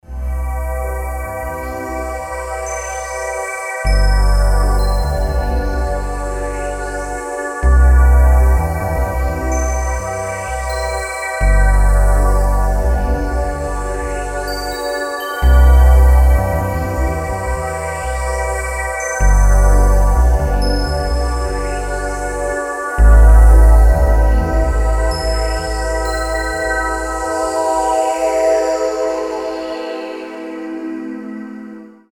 • Качество: 320, Stereo
красивые
электронная музыка
спокойные
без слов
инструментальные